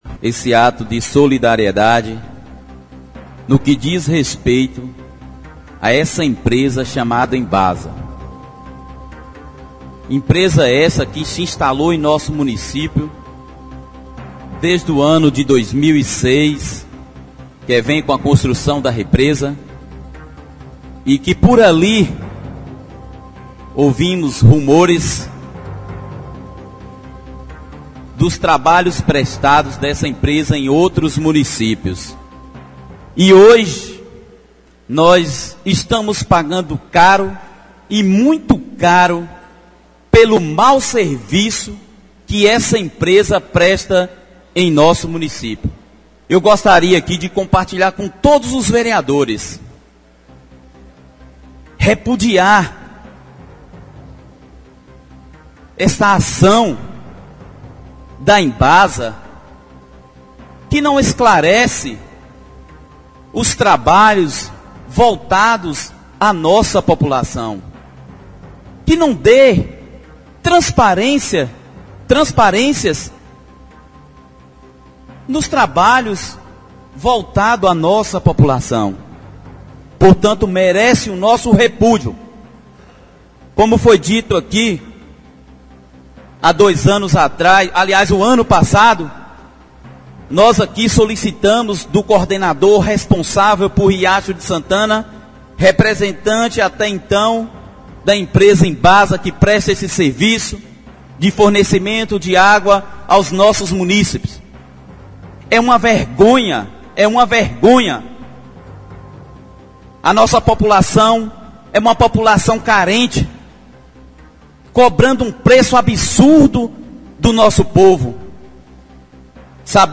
A denúncia chegou rapidamente na última sessão ordinária na Câmara de Vereadores de Riacho de Santana,  realizada na segunda-feira (11), onde, o presidente do Legislativo, Gilmar Ribeiro da Cruz (PP), revoltado com o aumento abusivo das contas, se manifestou.